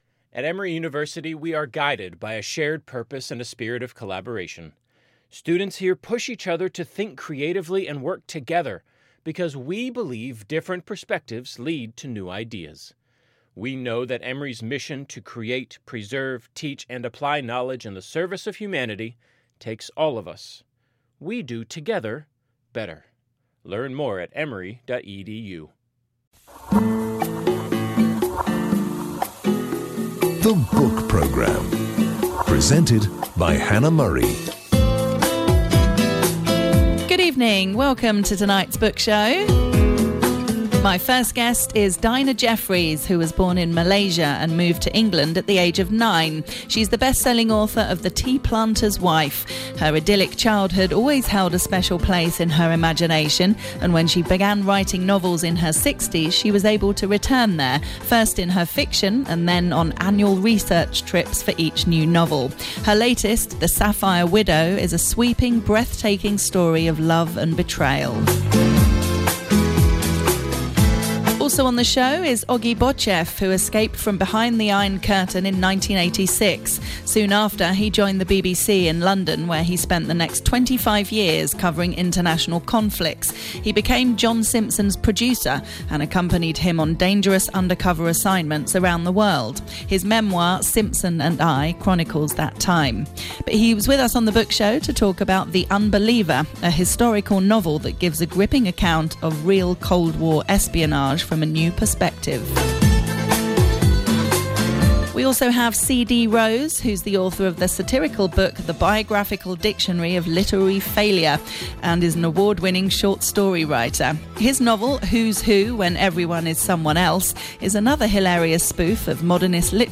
catches up with top authors, to discuss their latest releases